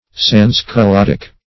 Search Result for " sans-culottic" : The Collaborative International Dictionary of English v.0.48: Sans-culottic \Sans`-cu*lot"tic\, a. Pertaining to, or involving, sans-culottism; radical; revolutionary; Jacobinical.